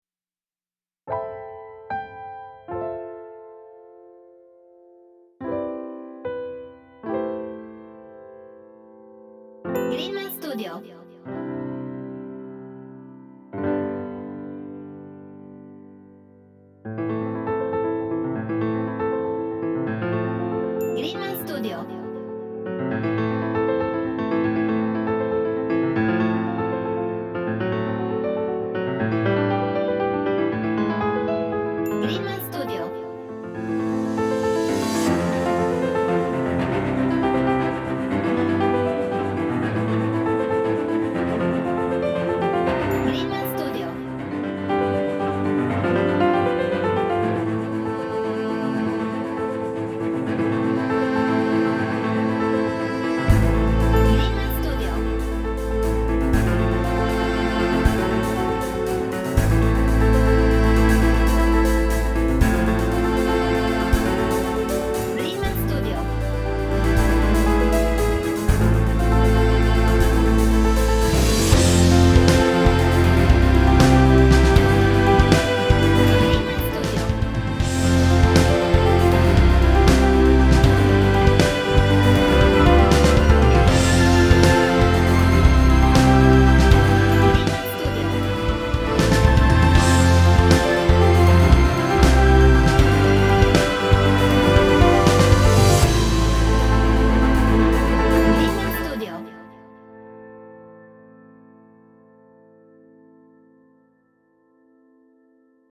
Orchestral/Cinematic
Epic